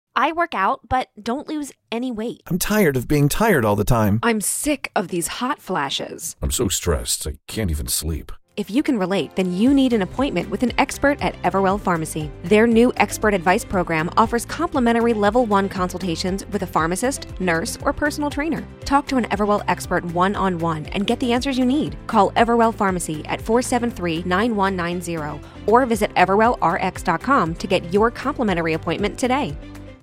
RADIO SPOT
When scripting, we wrote people speaking about ailments that one would not generally go to a doctor to treat, but were relatable conditions that could be resolved with vitamins and supplements.